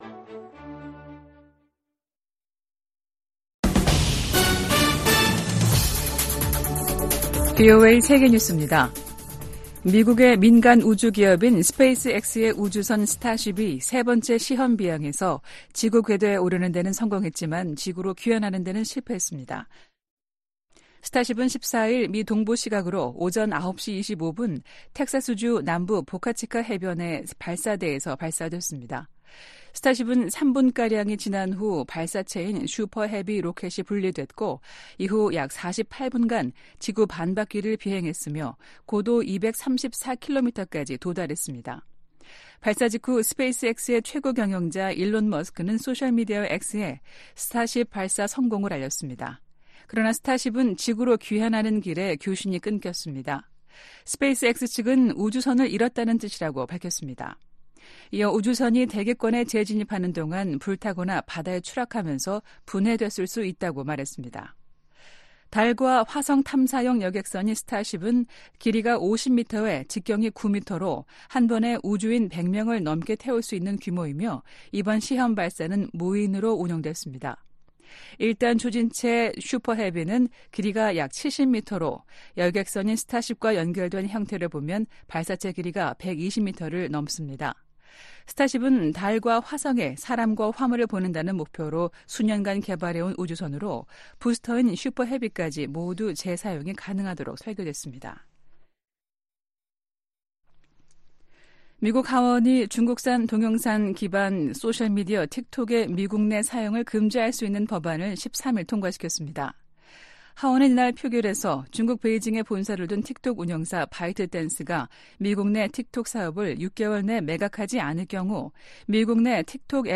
VOA 한국어 아침 뉴스 프로그램 '워싱턴 뉴스 광장' 2024년 3월 15일 방송입니다. 북한이 자체 핵우산을 가지고 있다는 블라디미르 푸틴 러시아 대통령의 발언에 관해, 미 국무부가 북-러 협력 강화에 우려를 나타냈습니다. 김정은 북한 국무위원장이 신형 탱크를 동원한 훈련을 현지 지도하면서 또 다시 전쟁 준비 완성을 강조했습니다. 미 의회 내 중국위원회가 북한 노동자를 고용한 중국 기업 제품 수입 즉각 중단을 행정부에 촉구했습니다.